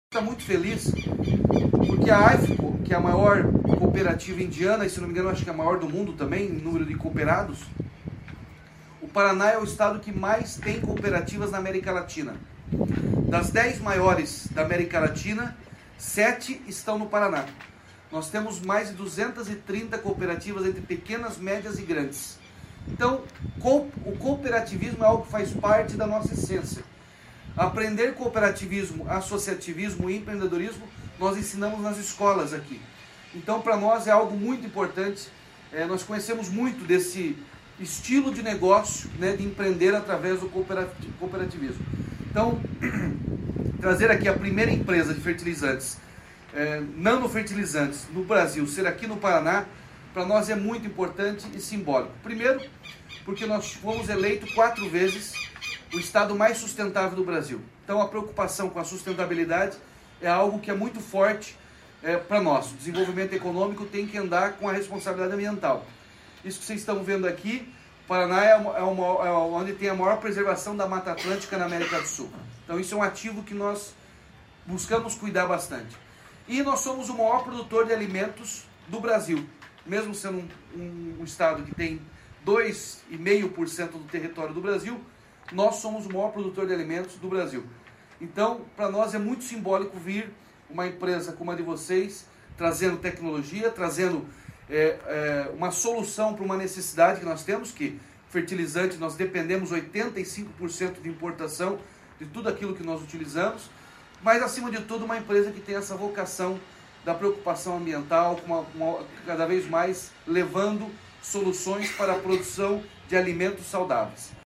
Sonora do governador Ratinho Junior sobre o anúncio da primeira fábrica de nanofertilizantes do Brasil no Paraná